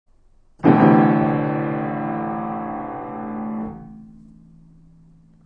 ピアノ演奏
Tr4=(効果音)神様が怒る音